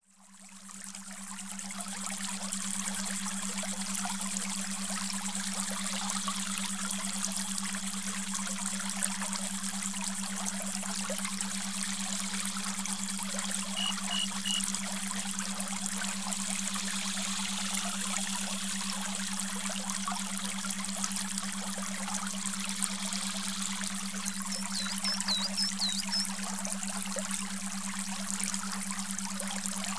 Beautiful nature scenes for relaxing.